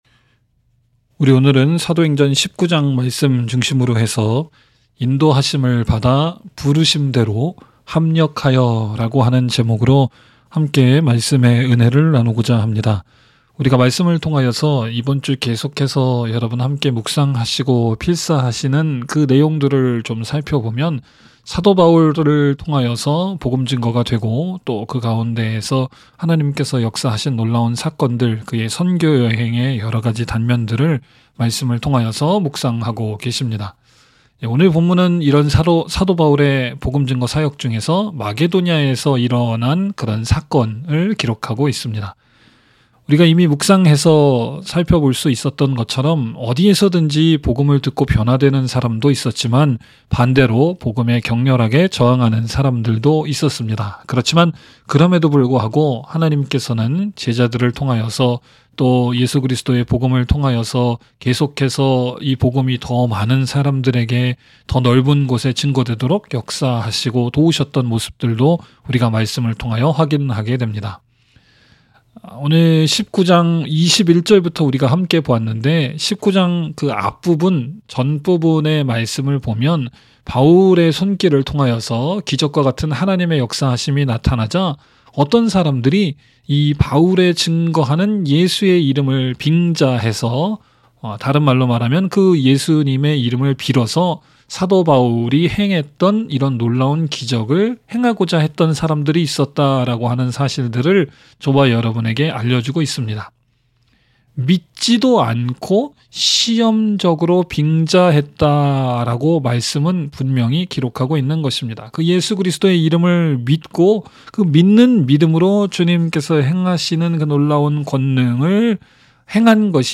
by admin | Feb 20, 2023 | 설교 | 0 comments